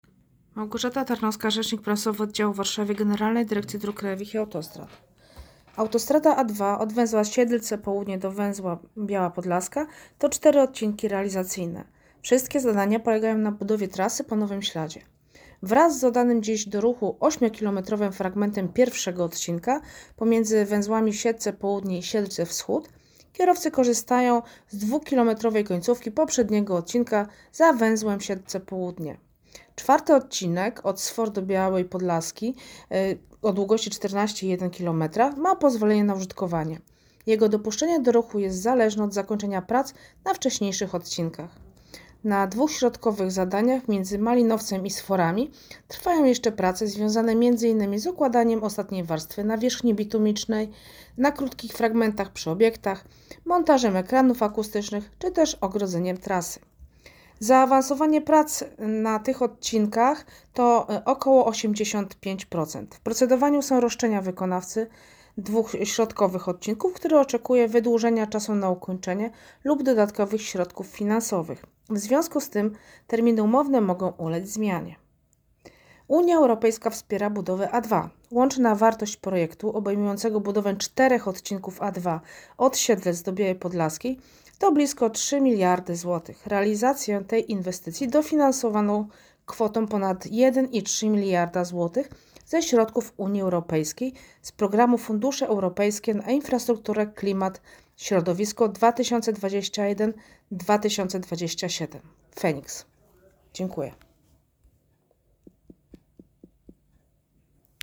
wypowiedź rzecznika - A2-Siedlce-BP